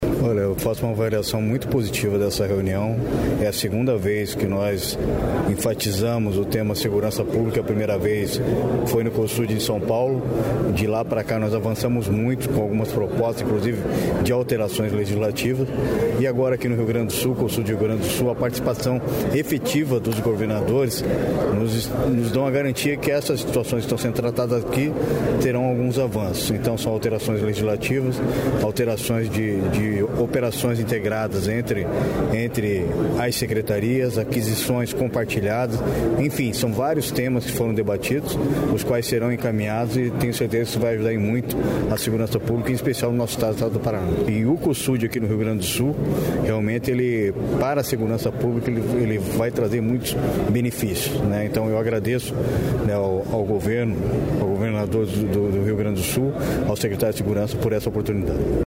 Sonora do secretário de Segurança Pública, Hudson Leôncio, sobre os temas tratados na reunião do Cosud